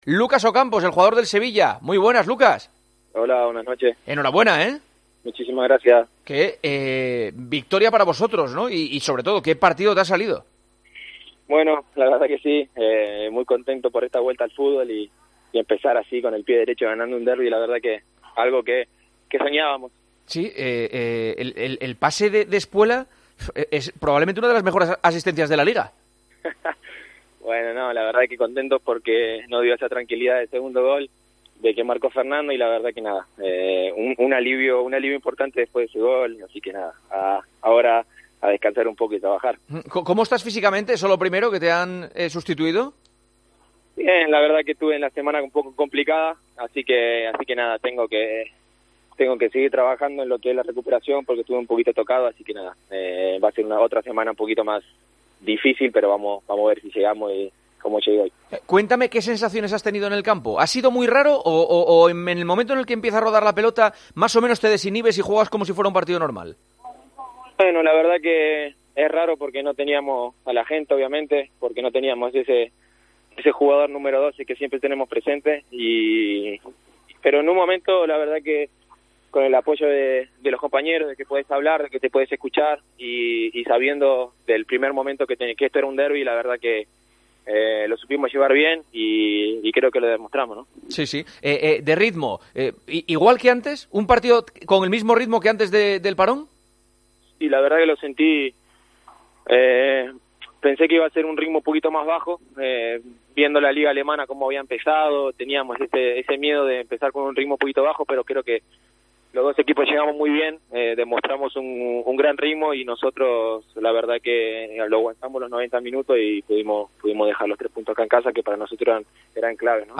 AUDIO: Hablamos con el artífice de la victoria del Sevilla ante el Betis